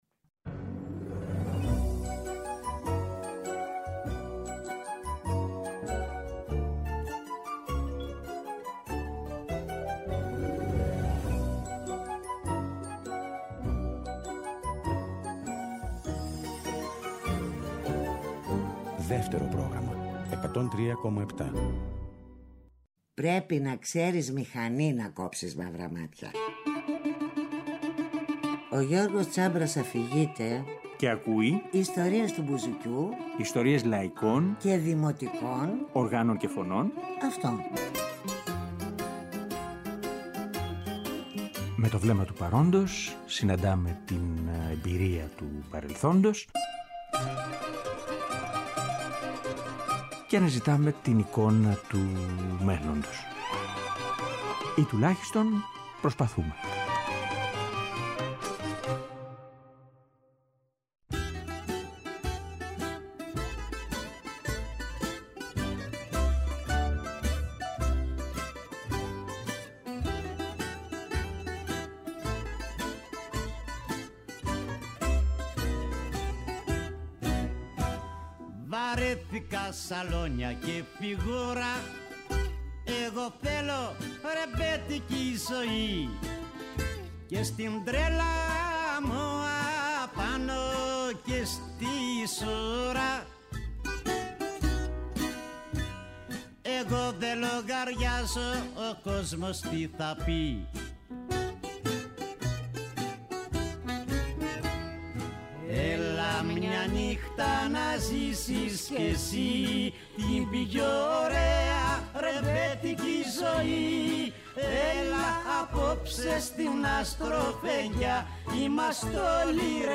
πιάνο
μπουζούκι